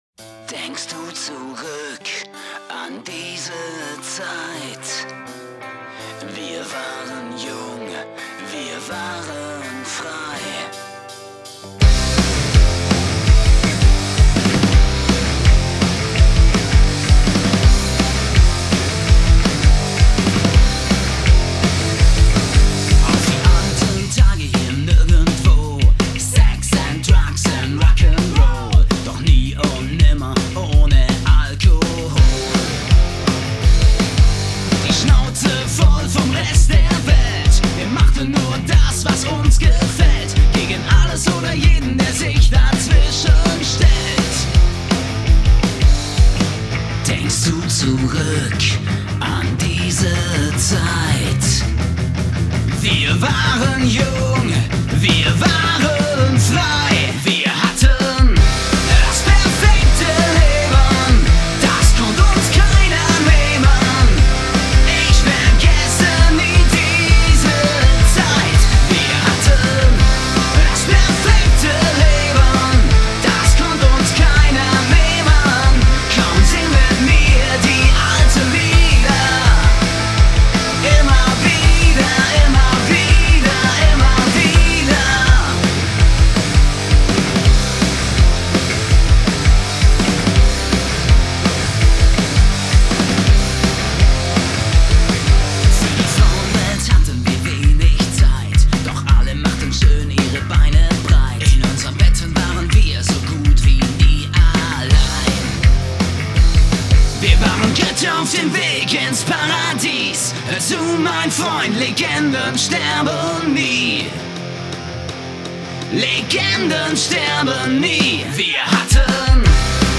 Gitarre, Schlagzeug, Bass